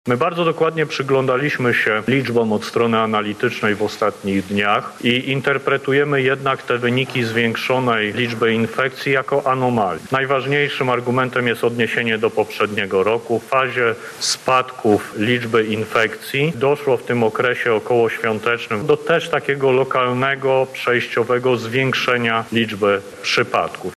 Musimy odpowiedzieć sobie na pytanie, jak interpretować te wyniki – mówi minister zdrowia Adam Niedzielski: